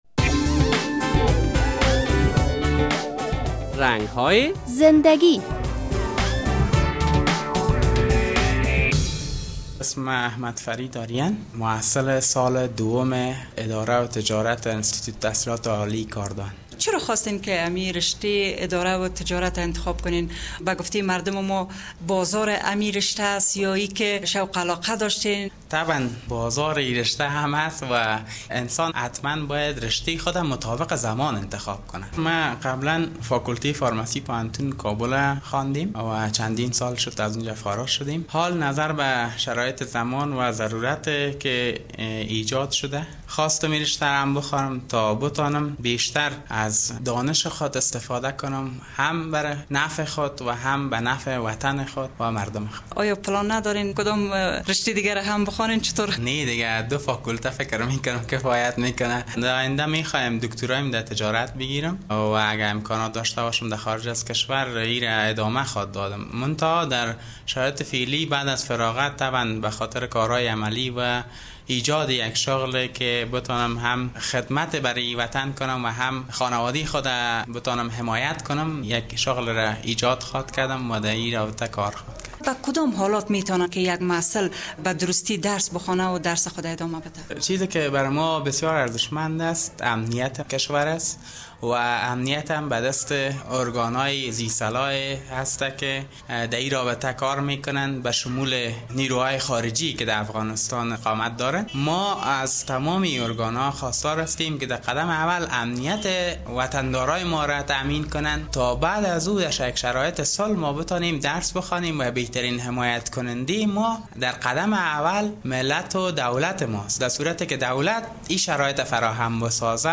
در این برنامهء در رنگ های زنده گی یک تن از محصلین موسسهء تحصیلات عالی کاردان به سوالات خبرنگار رادیو آزادی پاسخ ارایه کرده است...